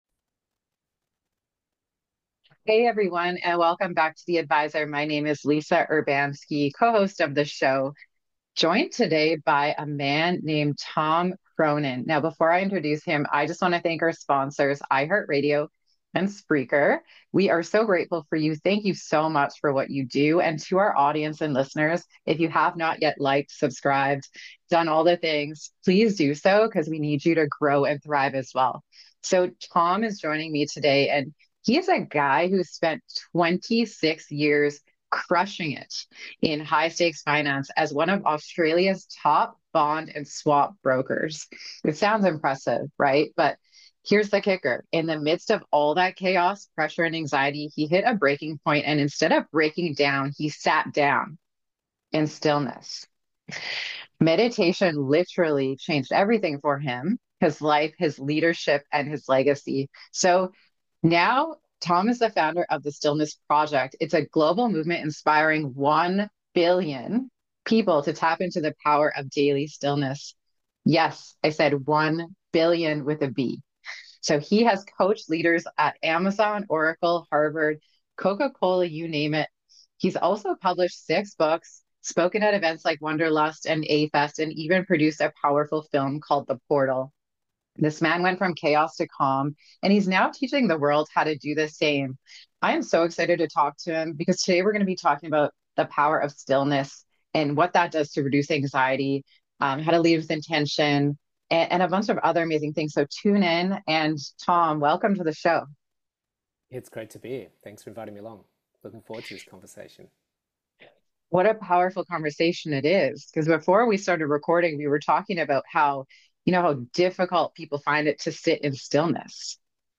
an insightful conversation